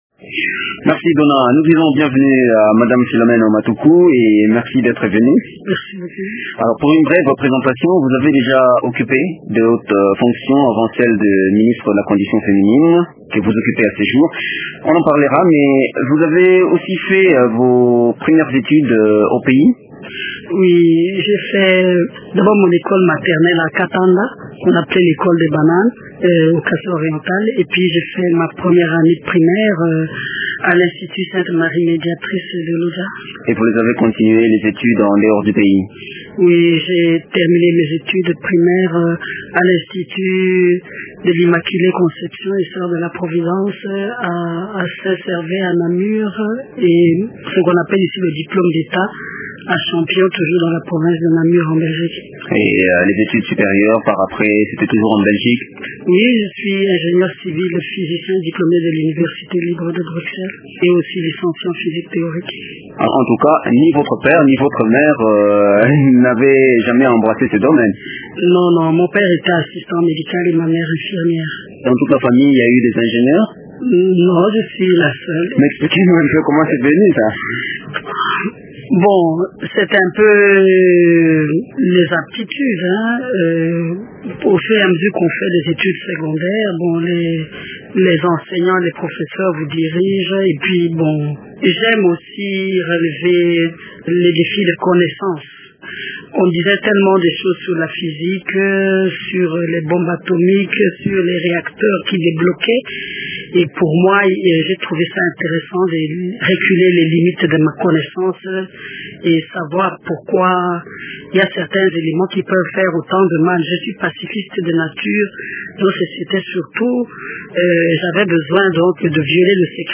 Madame Philomène Omatuku est ministre de la condition féminine.